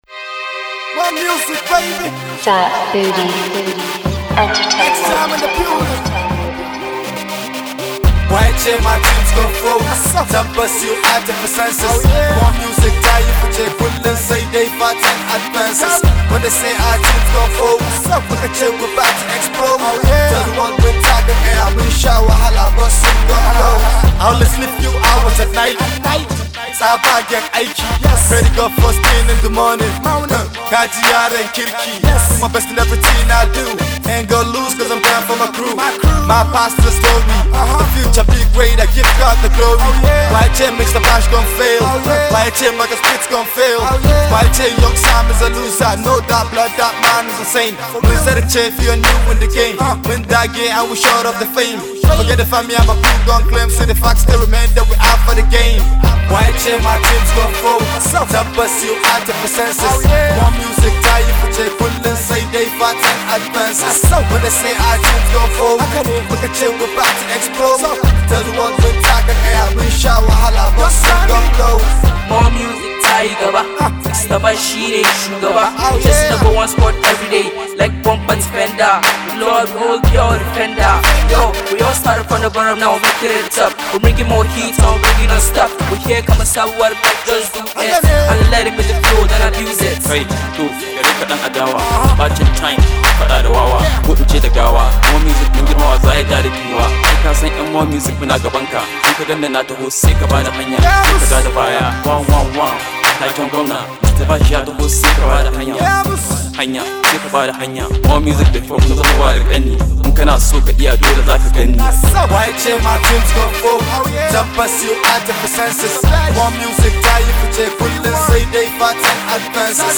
Alternative Rap
Young Hausa rapper